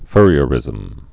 (frē-ə-rĭzəm)